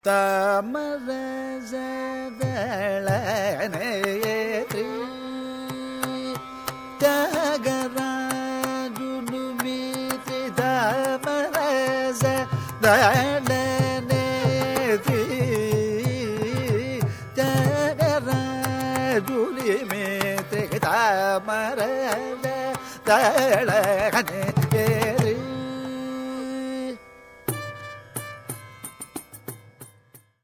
") set in rāga Bhairavi and Aditāla.
violin
Recorded in December 1967 in New York.
niraval: This includes a